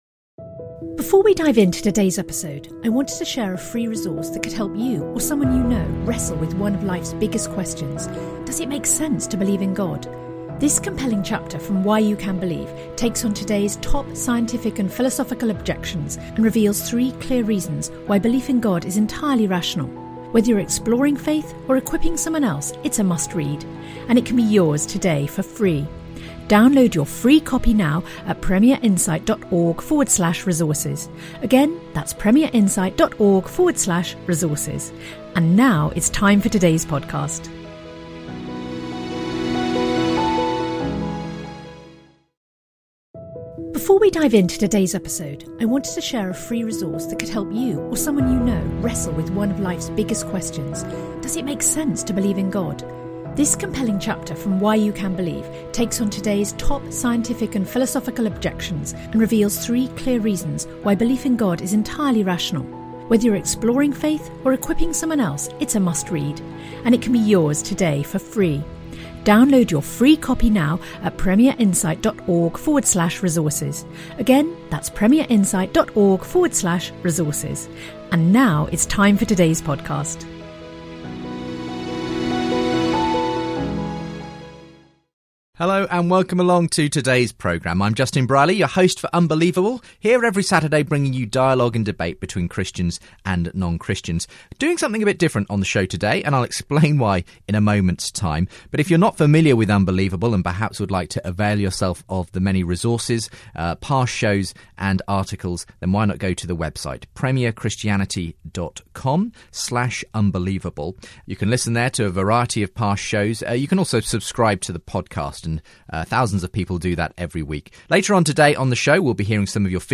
Up the Ladder in Hyde Park – Muslim & Christians in debate